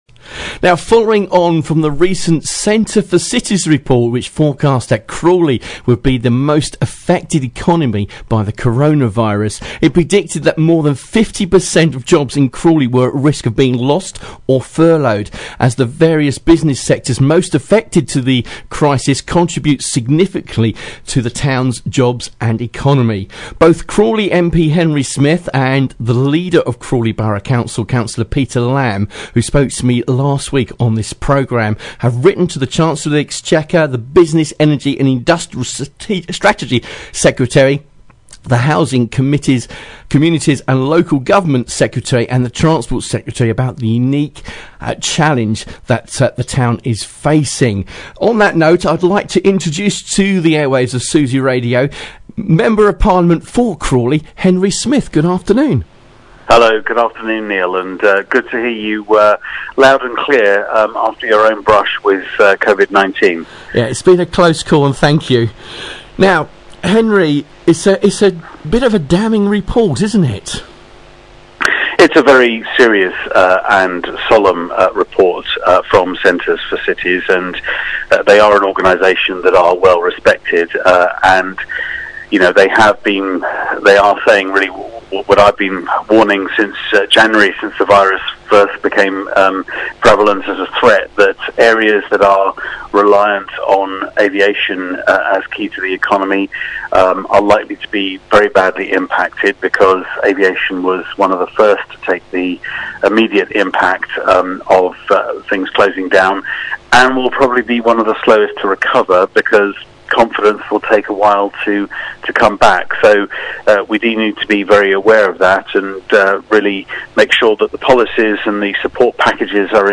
Speaking on Susy Radio, Henry Smith MP